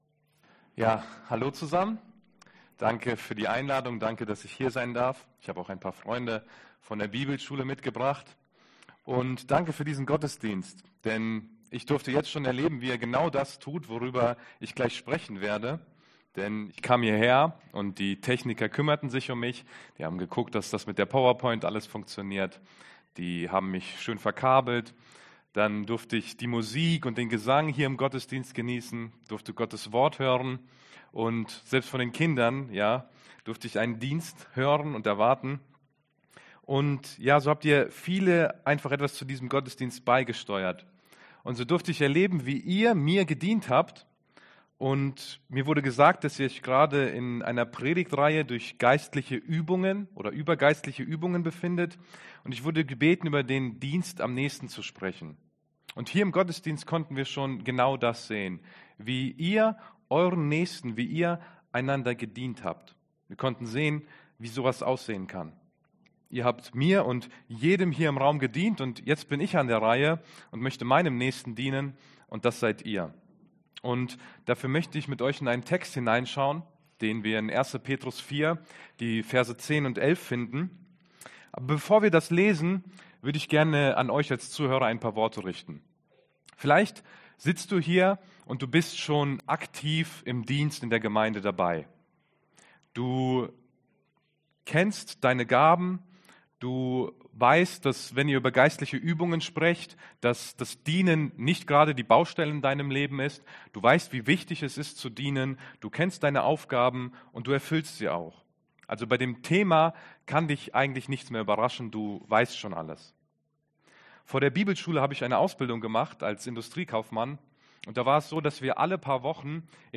Oktober 2025 Dienst am Nächsten Prediger